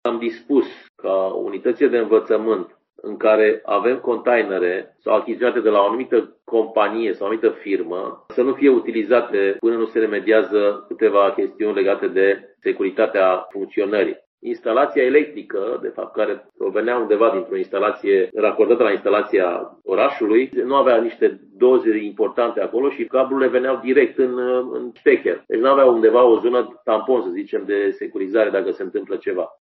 Inspectorul școlar general al județului Timiș, Marin Popescu.